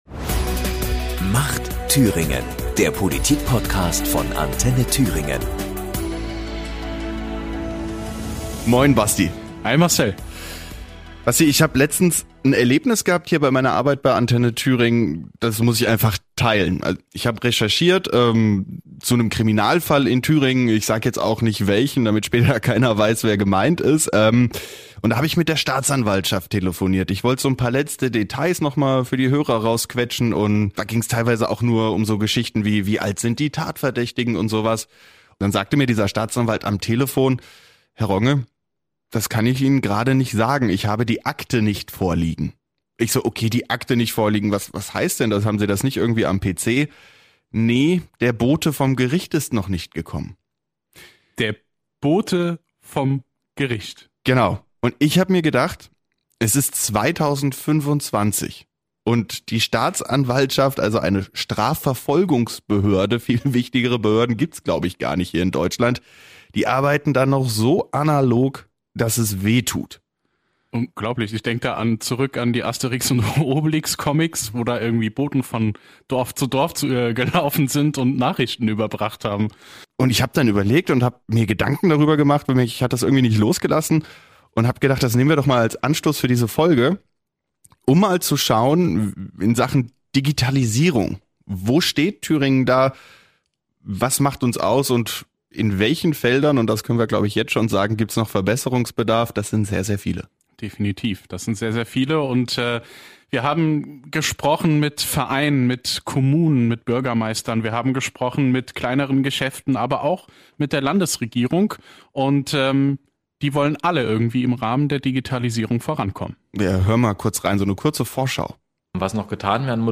Dafür reden sie mit Vereinen, Einzelhändlern, Kommunen und Bürgermeistern. Außerdem sprechen sie mit Thüringens Digitalminister Steffen Schütz darüber, wie die Zukunft des digitalen Thüringens aussehen soll. Dabei erfahren unsere Hosts unter anderem, warum es bald einen Thüringer App-Store geben wird.